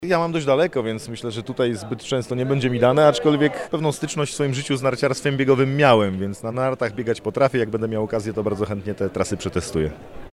Ministra Kamila Bortniczuka pytaliśmy czy będzie korzystał ze sportów w dolnośląskim centrum.